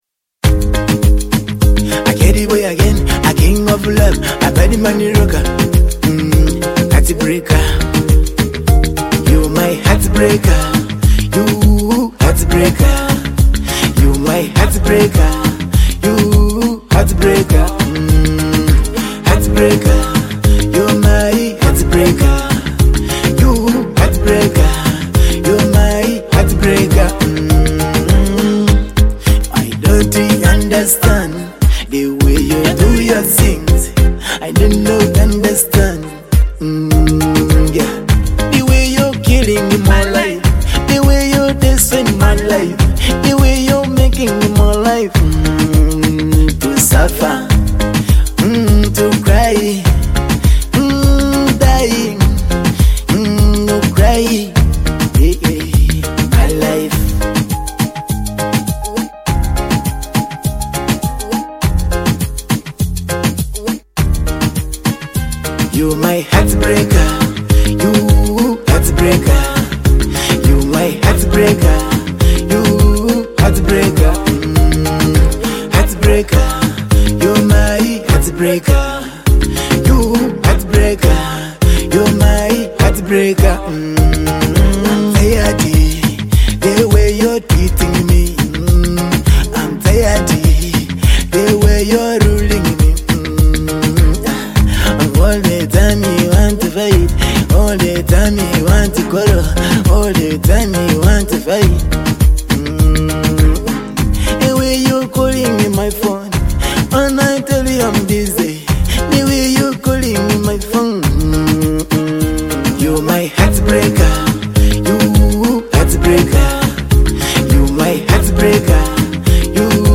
Uganda’s Rising Afrobeat Sensation!